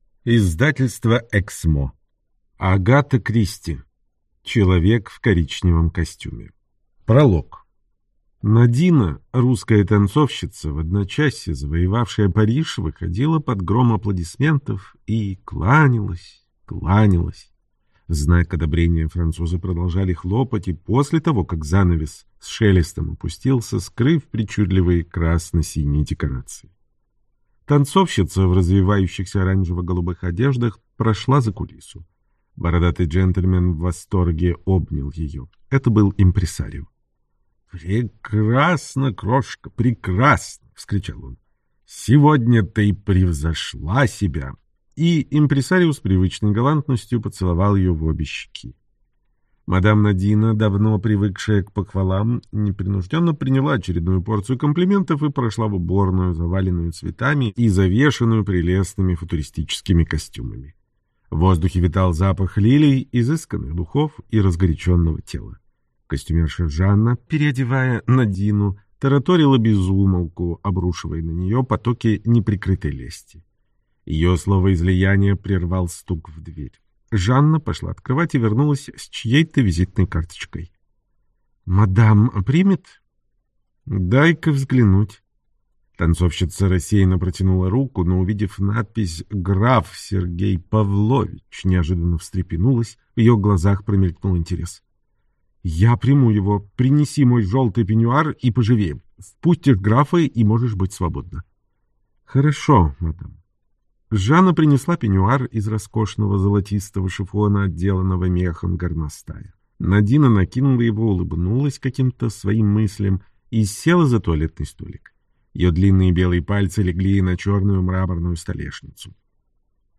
Аудиокнига Человек в коричневом костюме - купить, скачать и слушать онлайн | КнигоПоиск